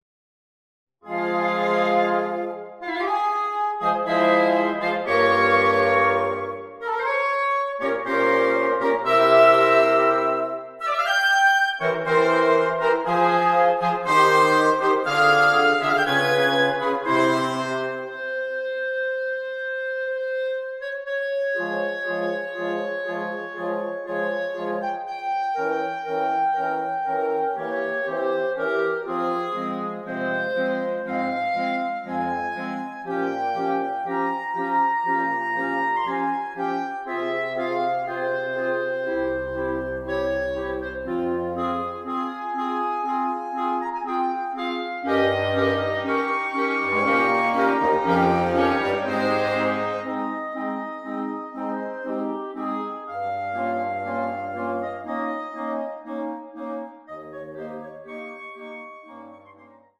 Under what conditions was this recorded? (Audio generated by Sibelius/NotePerformer)